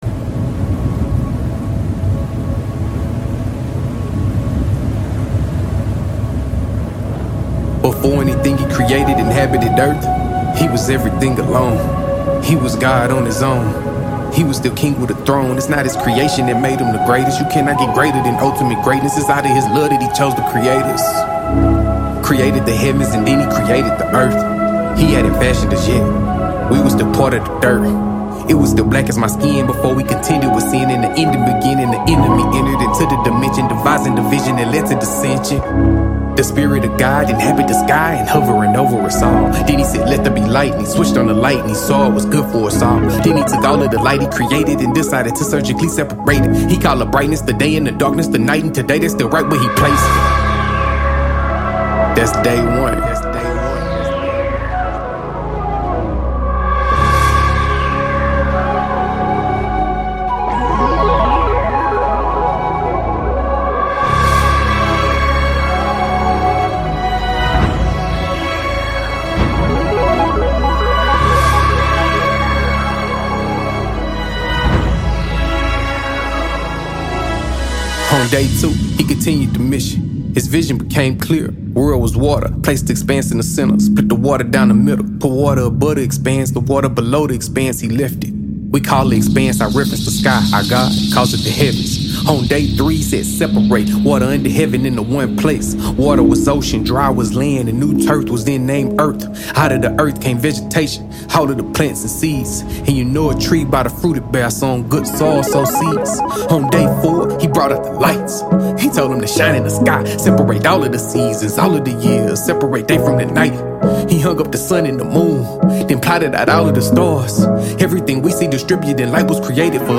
I Turned Gensis Into A Christian Rap